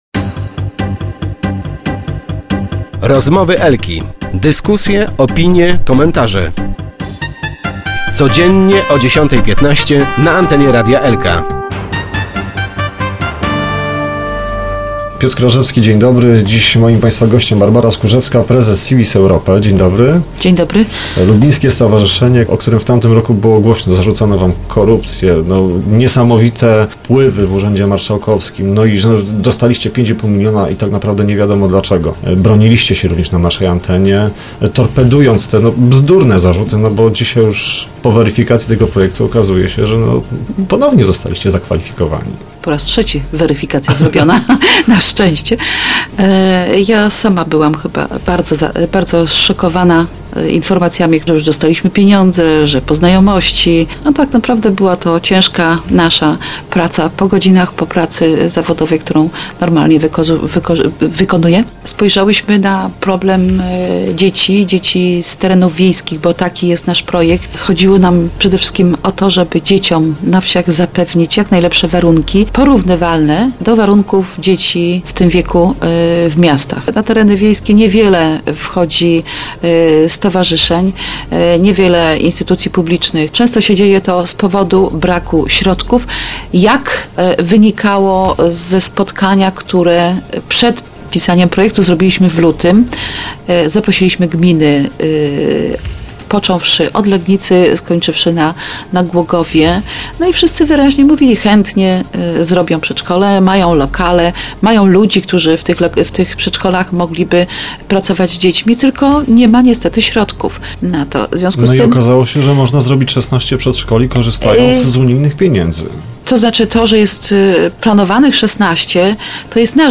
Start arrow Rozmowy Elki arrow Eksperci wykluczyli publiczne zarzuty
W naszym studio dociekała też dlaczego stowarzyszeniu zarzucono korupcję.